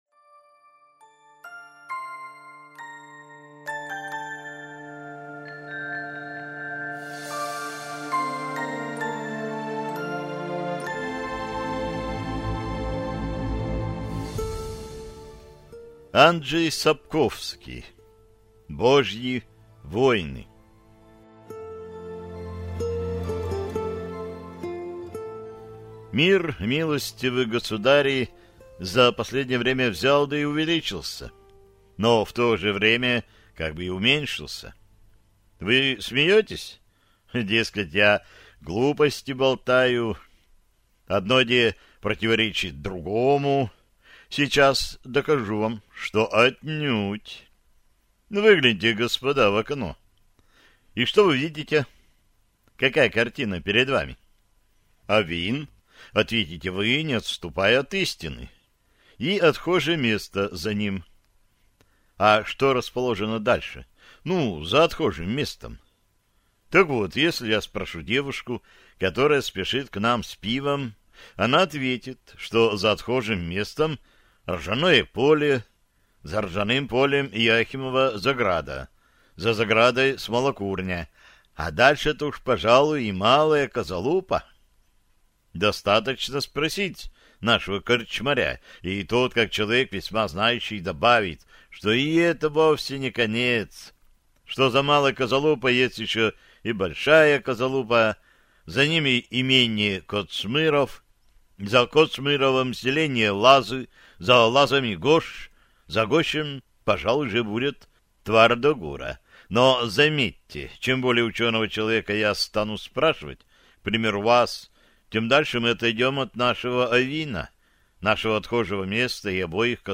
Аудиокнига Божьи воины - купить, скачать и слушать онлайн | КнигоПоиск